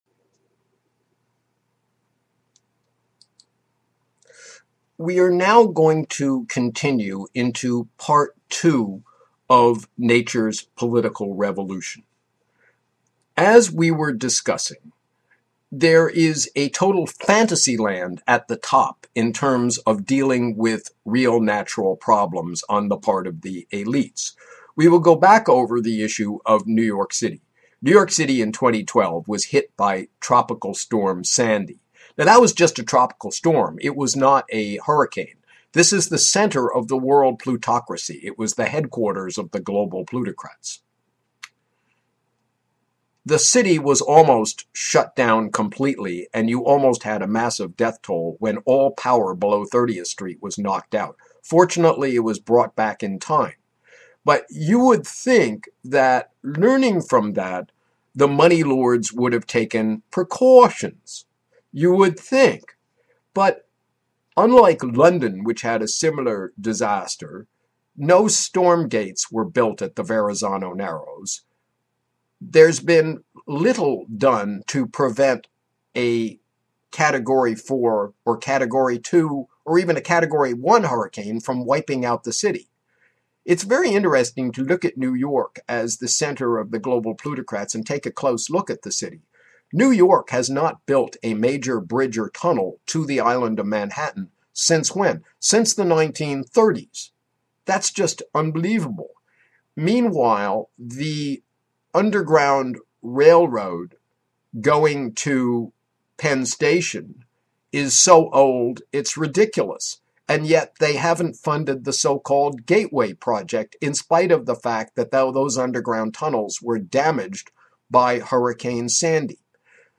LECTURE I (10 MINUTES) LECTURE II (13 MINUTES)